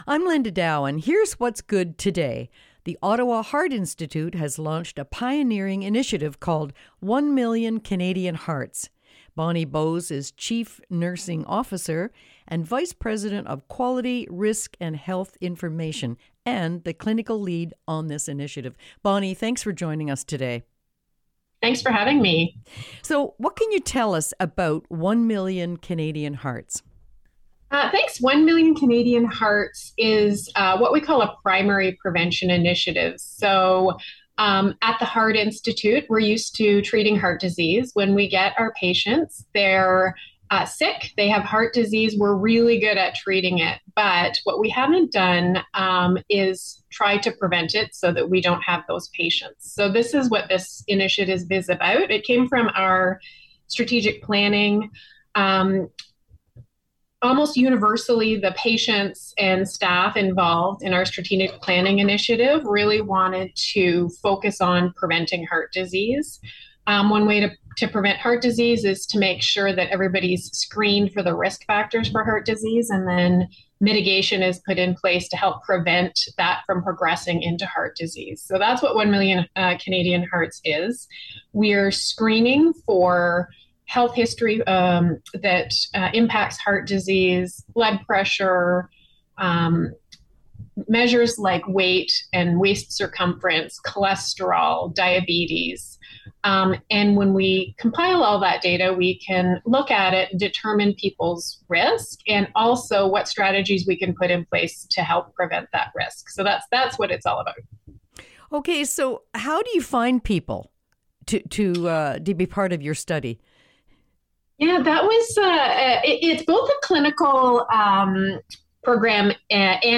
complete conversation